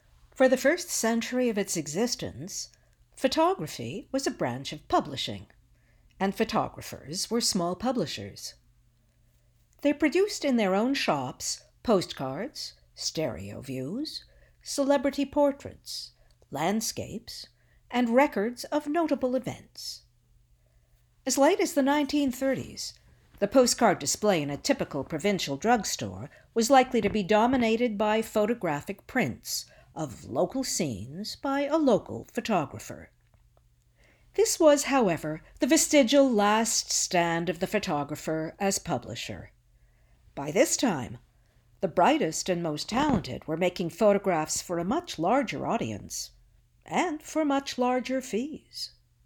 Narration - ANG